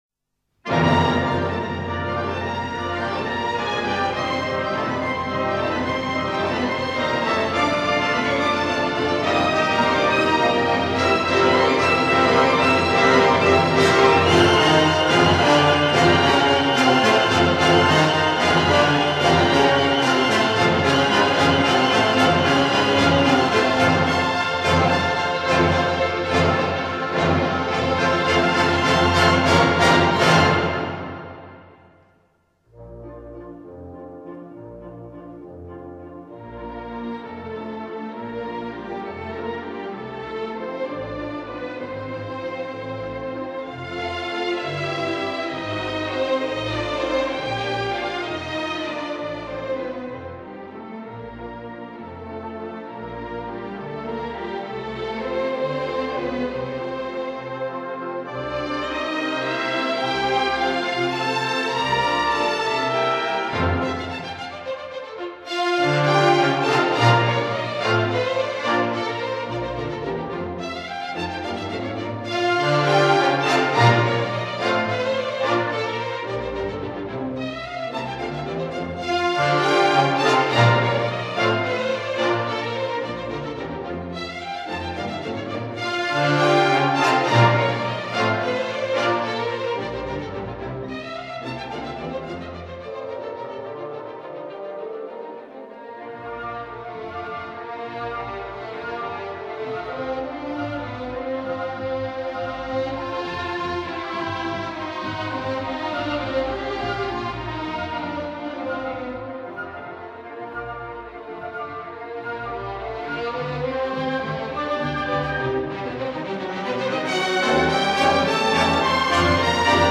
"The Swan Lake"Ballet Suite 《天鹅湖》芭蕾组曲